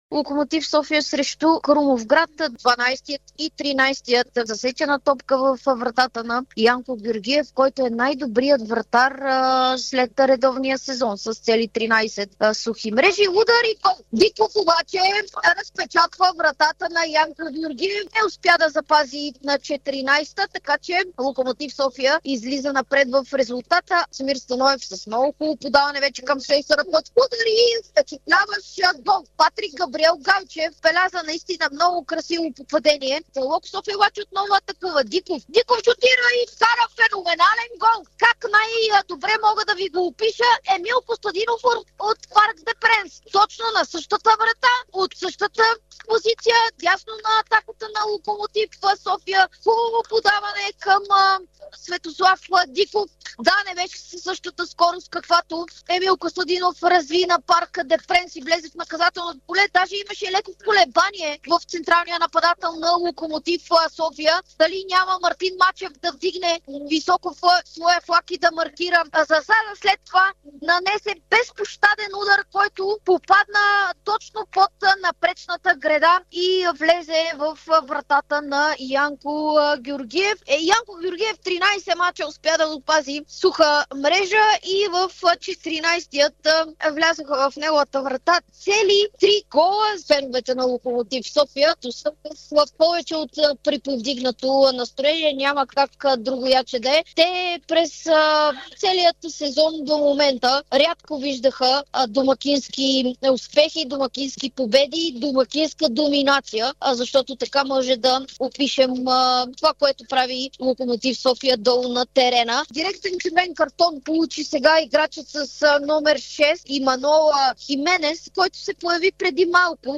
През очите на коментаторите на Дарик, какво чухме от родните футболни терени в първия кръг от плейофите, в който Лудогорец си осигури 14-а поредна титла, отново се чуха скандирания "Оставка" от феновете на Левски след равенството с Арда, ЦСКА постигна труден успех срещу Спартак Варна, Битката за Тракия завърши без победител, а Локомотив София и Славия записаха победи в долната осмица.